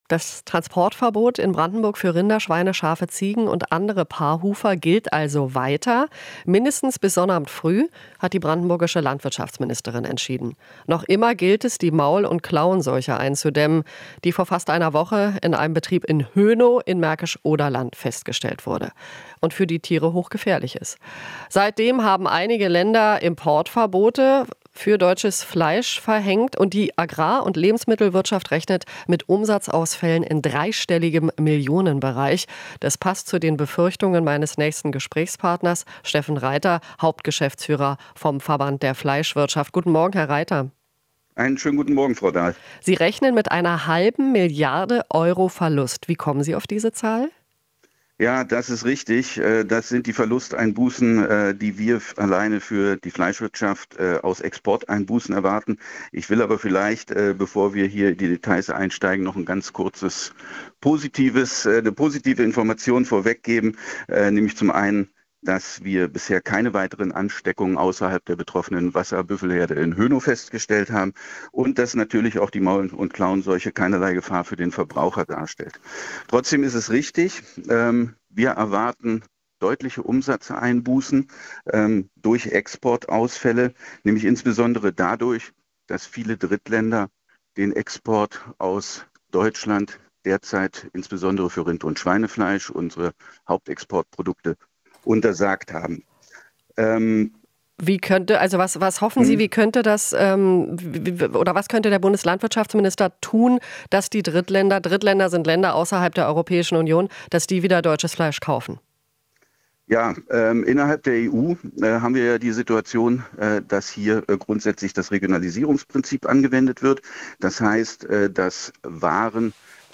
Interview - MKS: Fleischwirtschaft befürchtet Milliardenverlust